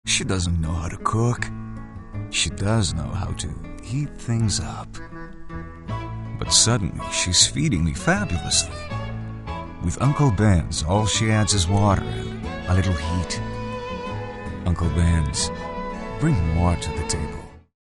brazilian portuguese voice actor, voiceover, Brazil, Brasil, locutor, brasileiro, brazilian vo actor
VOICEOVER DEMOS [playlist images="false" artists="false".